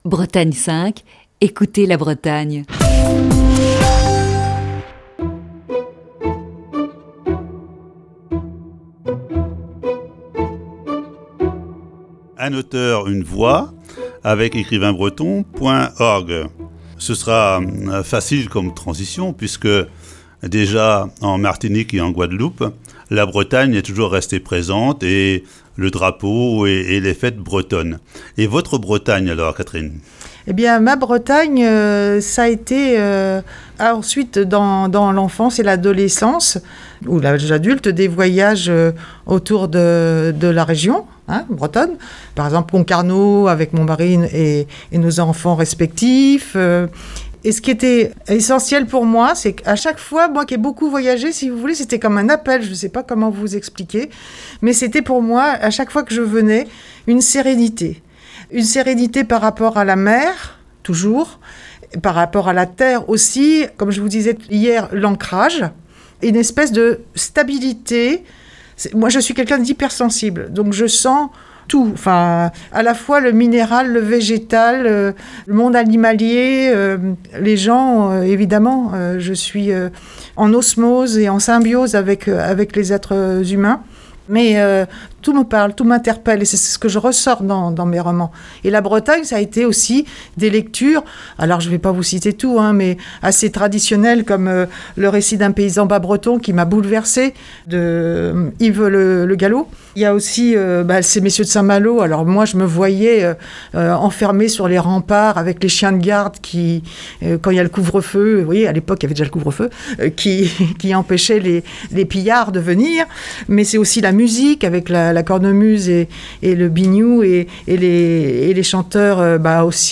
Ce matin, deuxième partie de cette série d'entretiens.